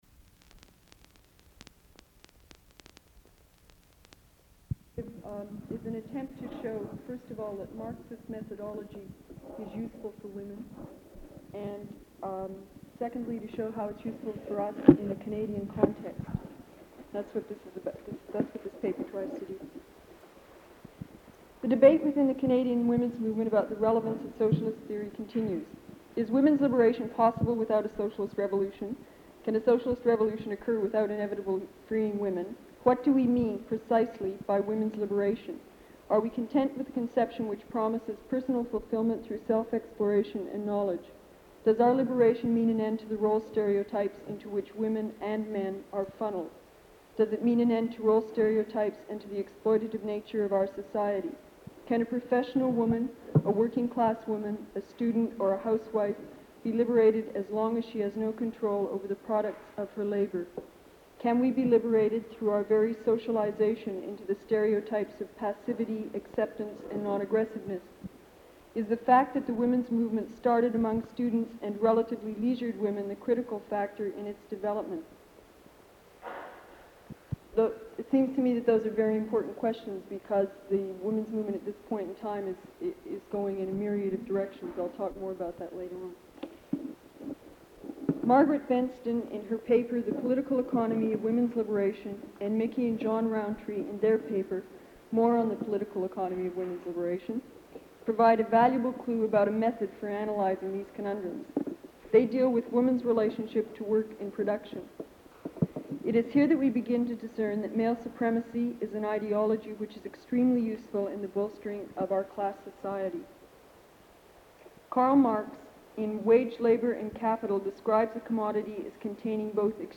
Recording of an address